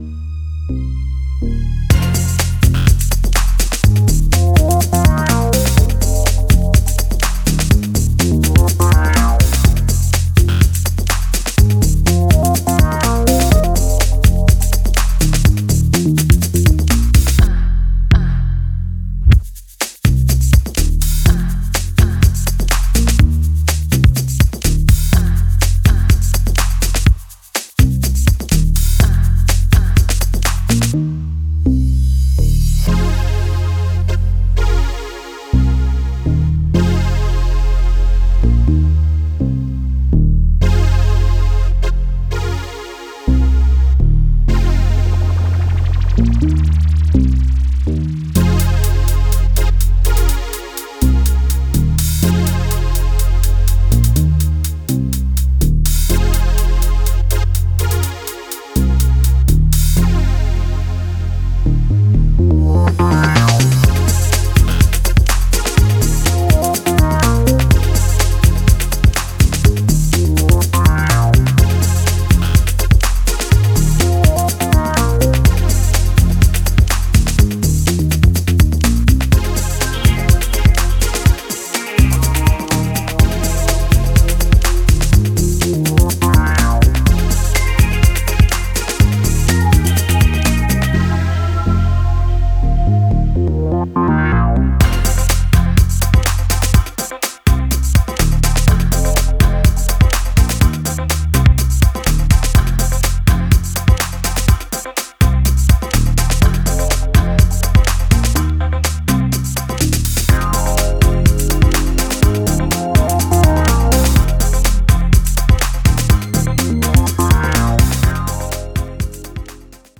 offering a rich blend of electronic sounds